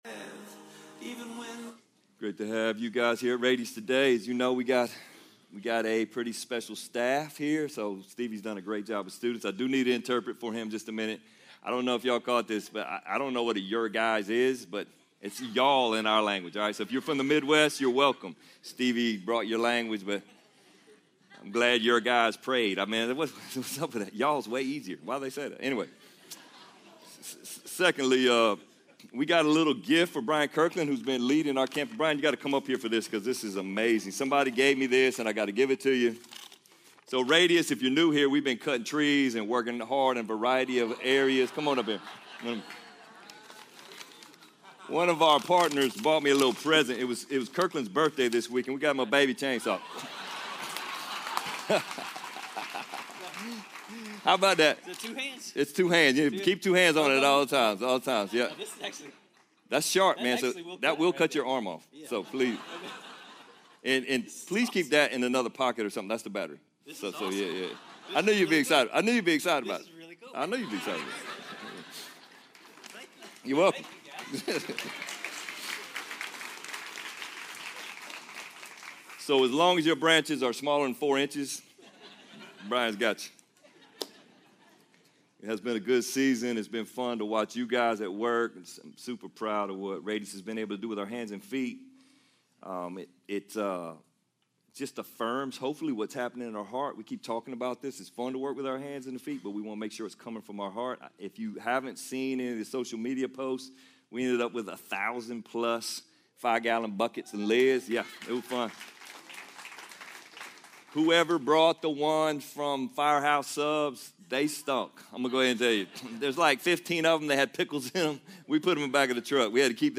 Sermon Library | RADIUS Church